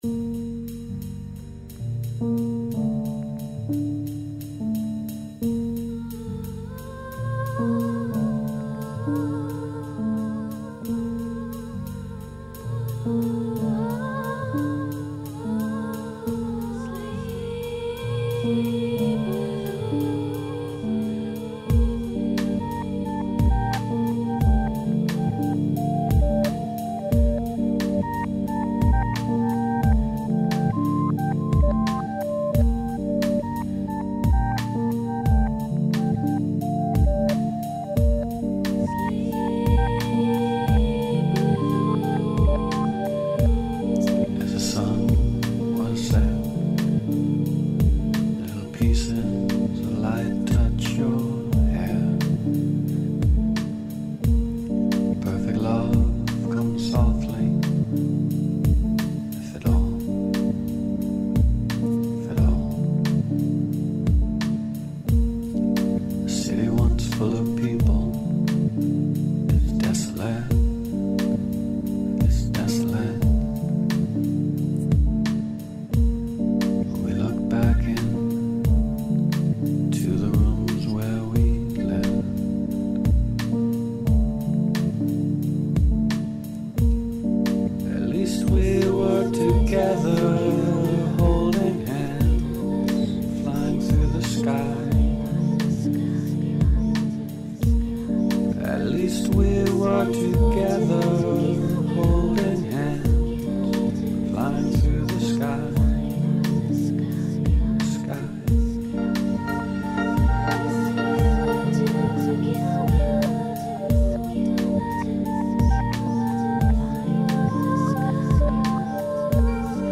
Added Backing Vox: